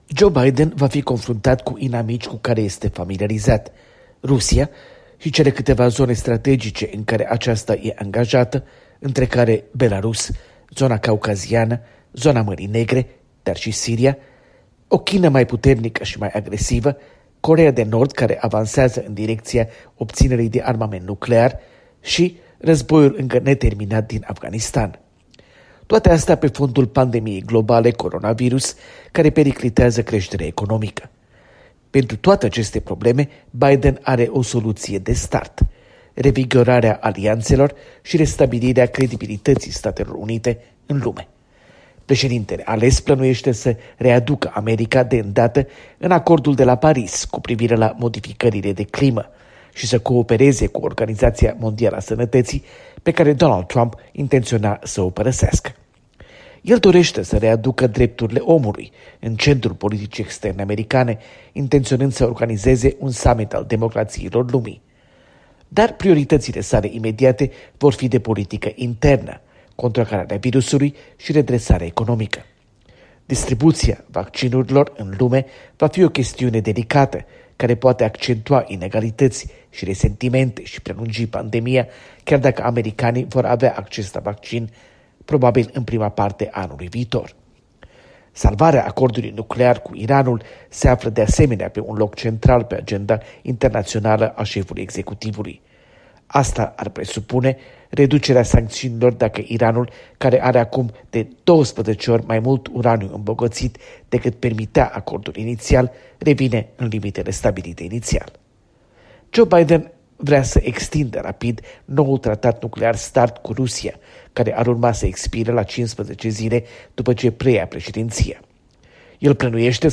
Corespondență de la Washington